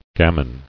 [gam·in]